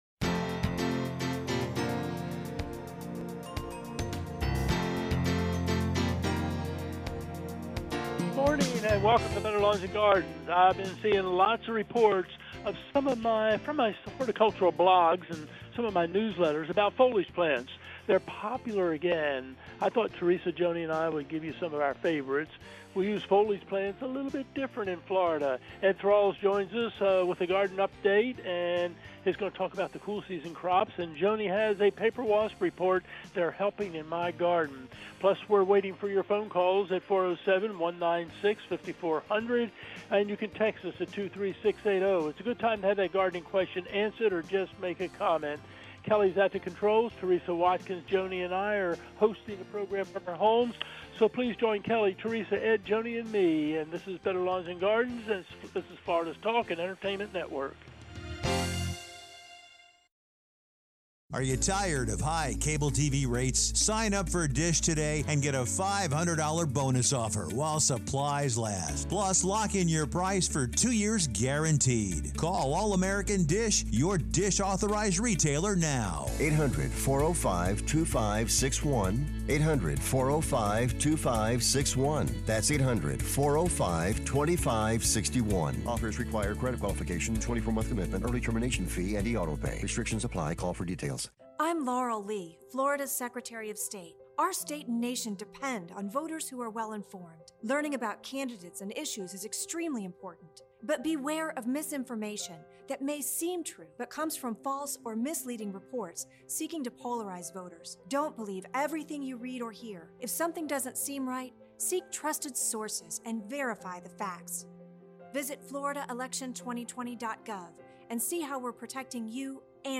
Florida's most popular gardening show with gardening experts